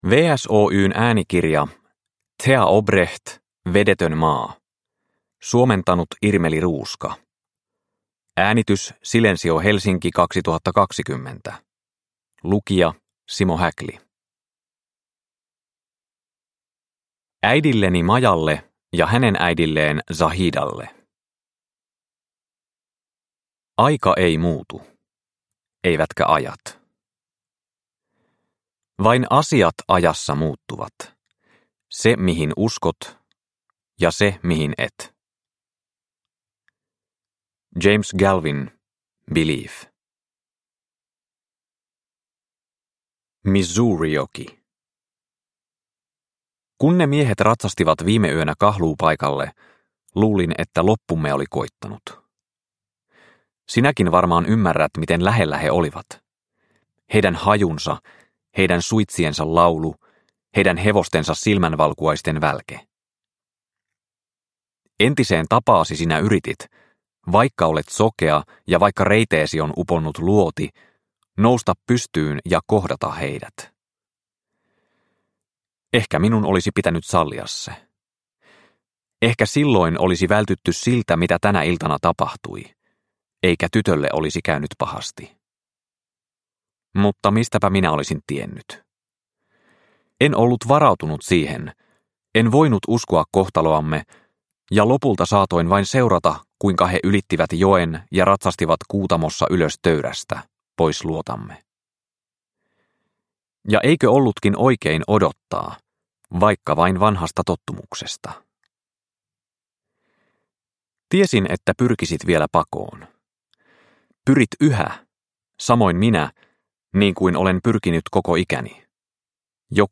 Vedetön maa (ljudbok) av Téa Obreht